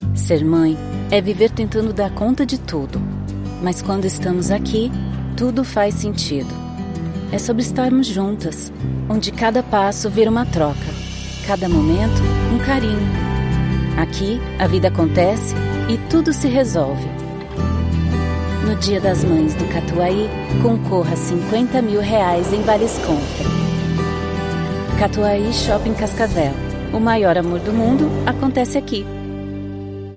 Emotivo
Dê mais vida aos seus projetos com uma voz feminina moderna, profissional e cativante!